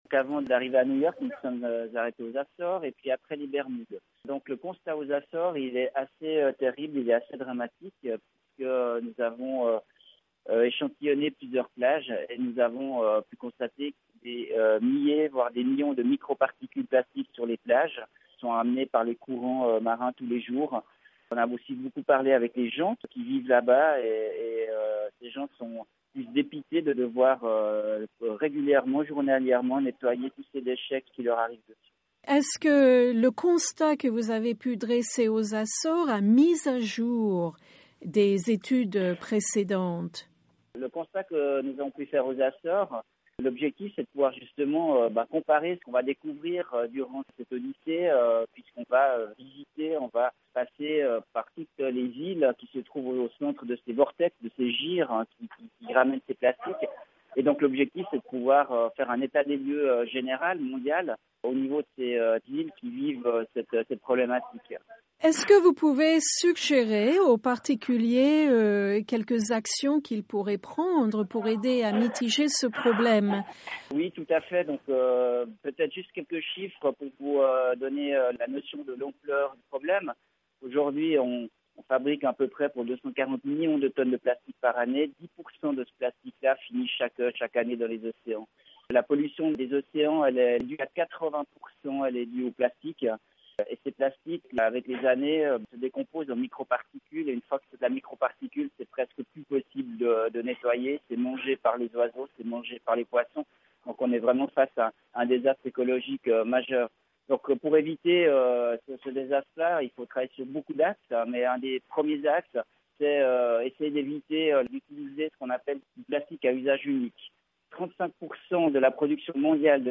Dans une interview